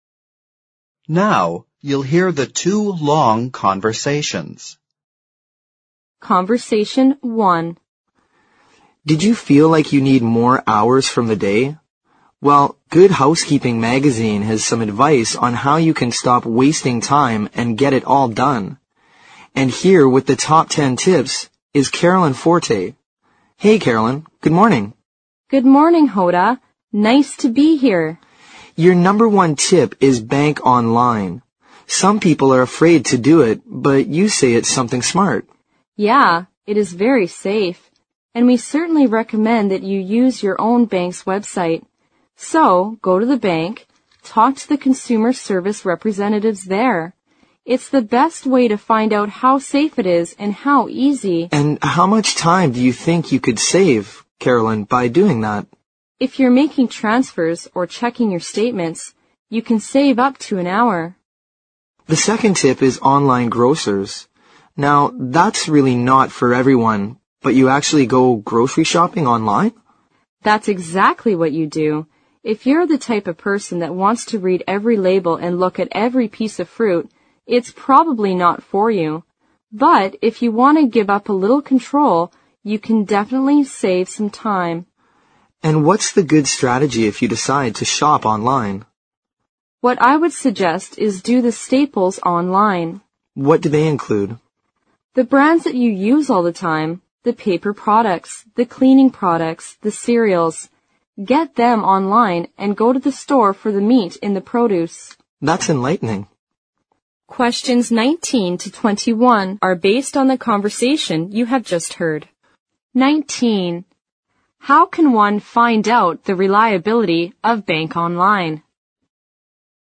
Conversation One